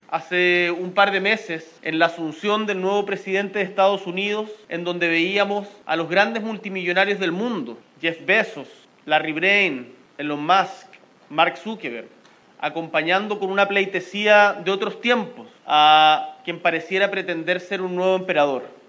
Tras dicho punto de prensa, Boric se trasladó al Taj Mahal en el marco de esta visita de Estado.